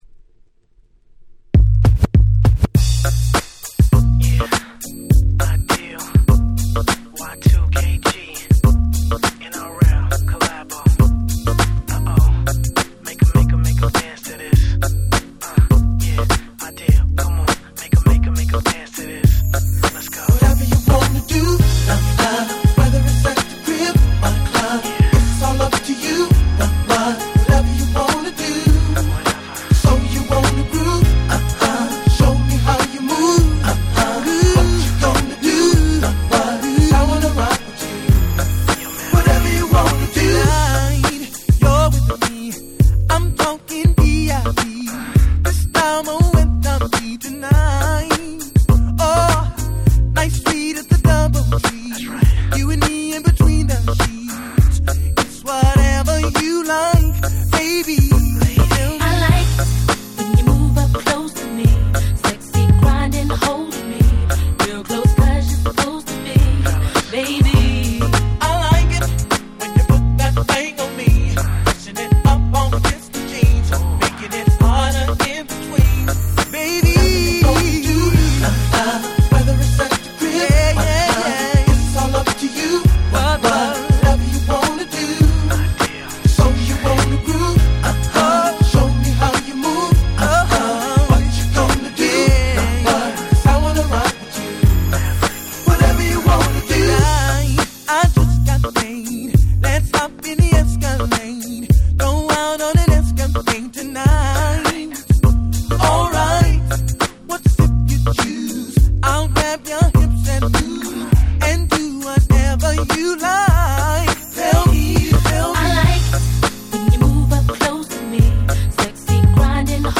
00' Smash Hit R&B♪
90's R&Bファンでも絶対にイケる、正当派超絶Smooth Tune !!